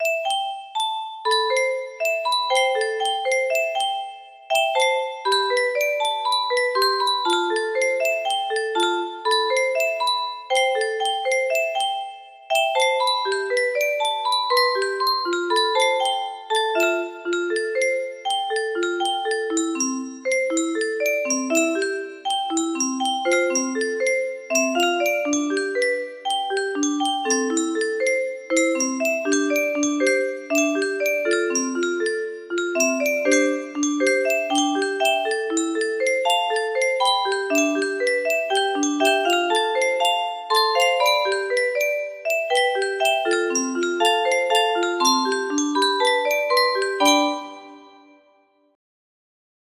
double tempo